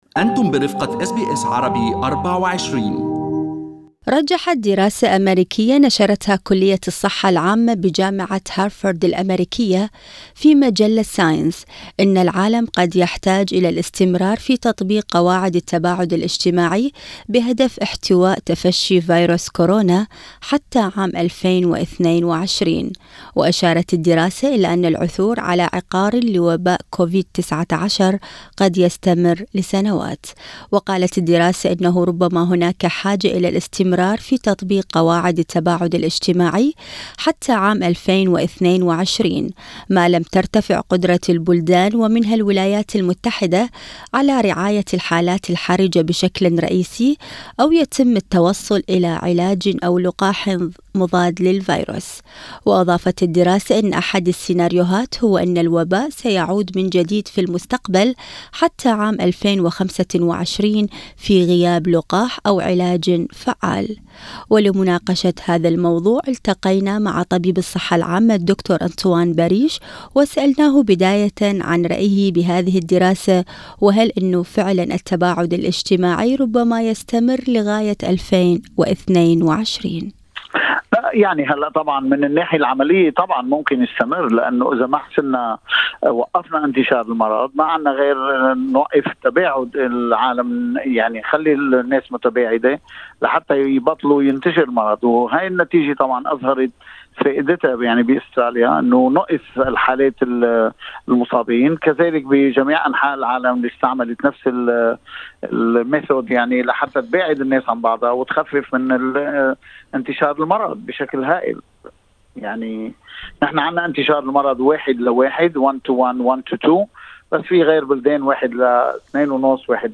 ولمناقشة هذا الموضوع، التقى برنامج أستراليا اليوم بطبيب الصحة العامة